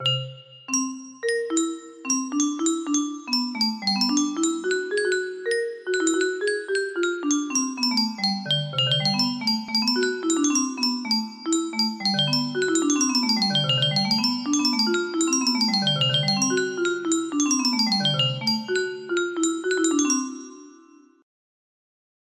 chair de lune music box melody